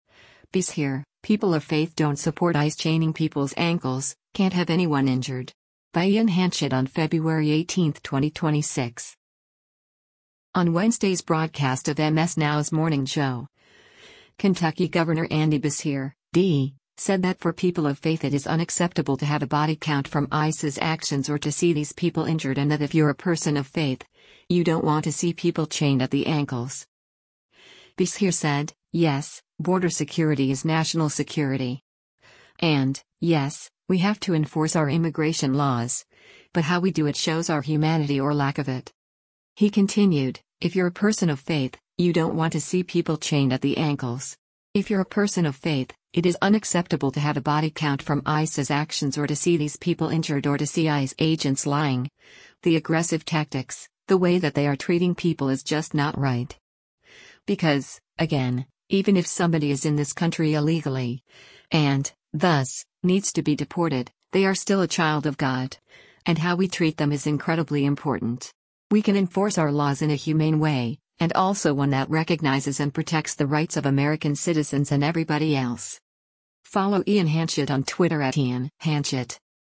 On Wednesday’s broadcast of MS NOW’s “Morning Joe,” Kentucky Gov. Andy Beshear (D) said that for people of faith “it is unacceptable to have a body count from ICE’s actions or to see these people injured” and that “If you’re a person of faith, you don’t want to see people chained at the ankles.”